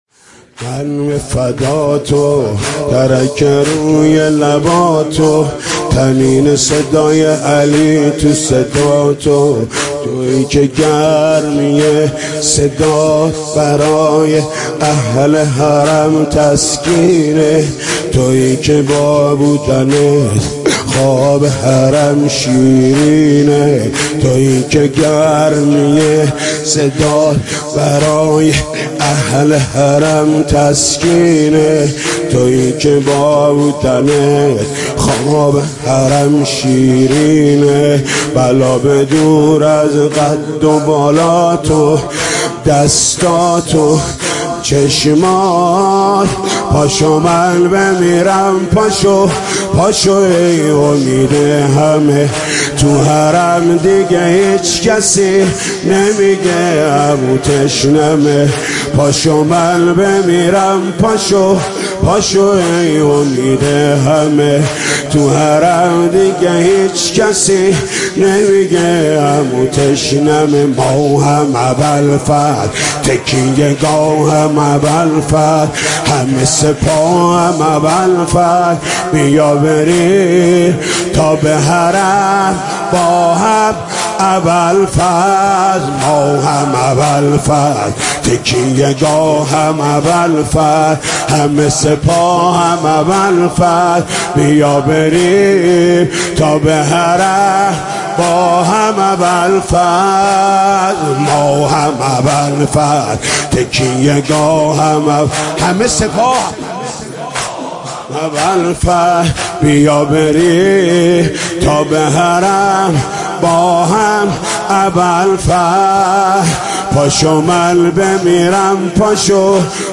شب نهم محرم 96 محمود کریمی
نوحه جديد محمود کريمی
مداحی صوتی محمود کريمی
من به فدا تو تَرَك روى _ زمینه محمود کریمی شب نهم محرم 96/07/07